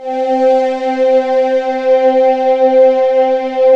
Index of /90_sSampleCDs/USB Soundscan vol.28 - Choir Acoustic & Synth [AKAI] 1CD/Partition D/09-VOCODING
VOCODINGC4-L.wav